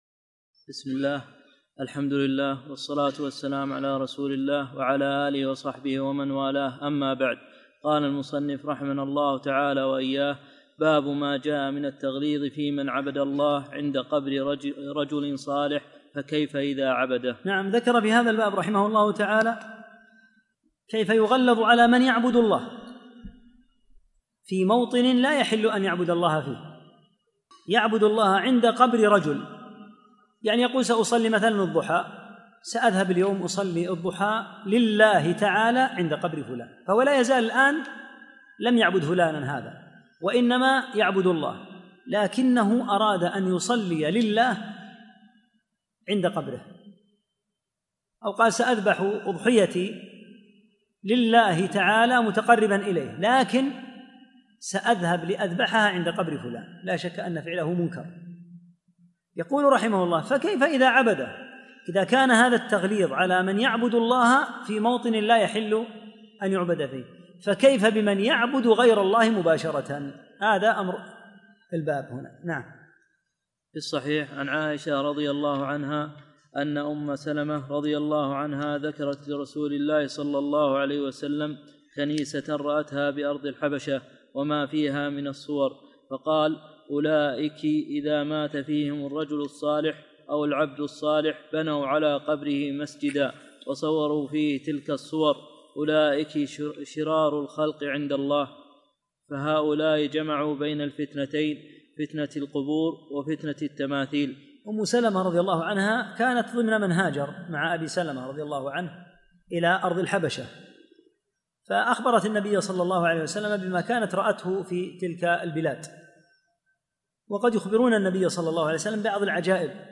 20- الدرس العشرون